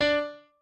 piano7_7.ogg